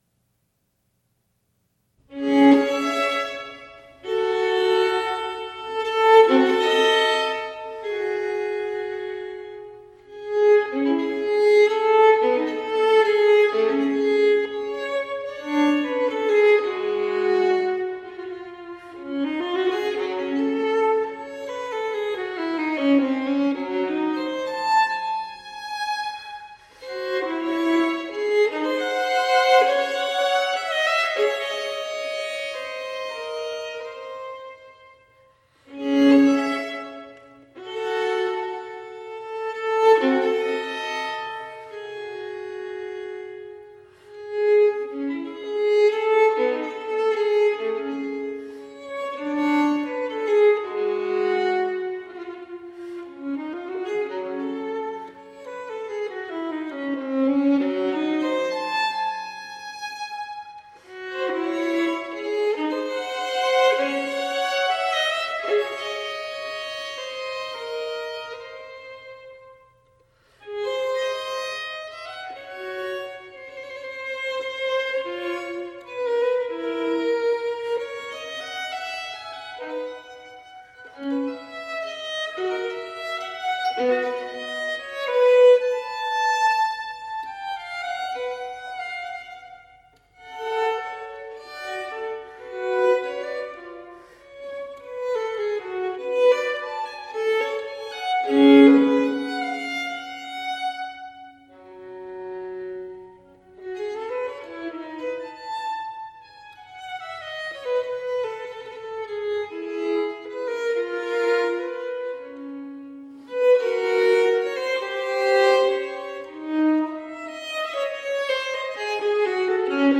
Lilting renaissance & baroque vocal interpretations .